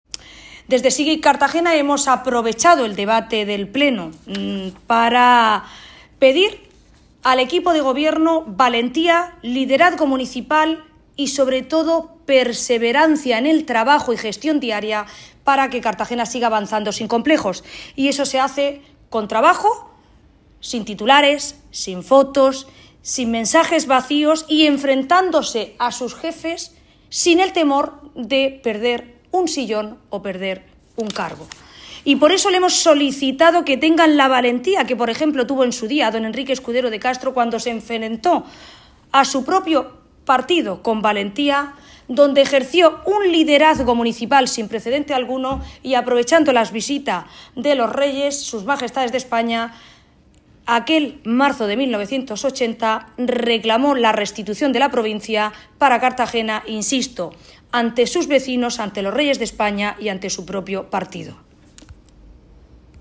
Audio: Declaraciones de Ana Bel�n Castej�n (1) sobre Cartagena (MP3 - 1.006,61 KB)